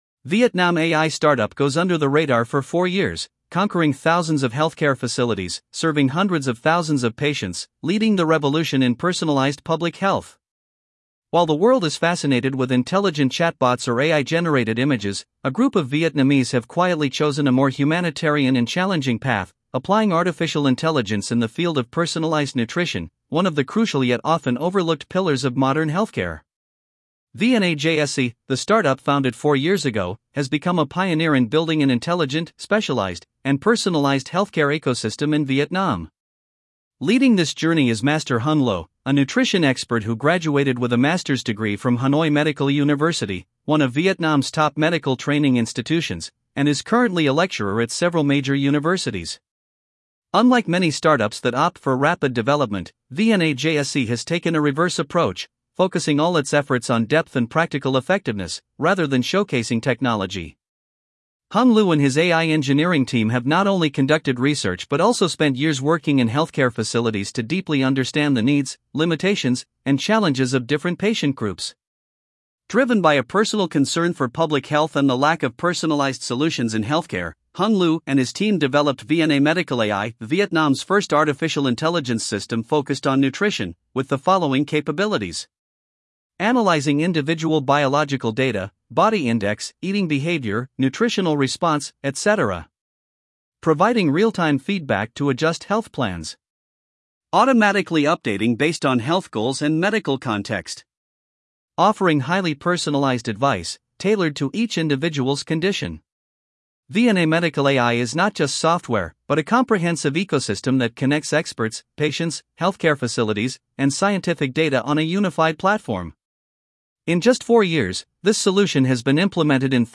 giong-nam-EL.mp3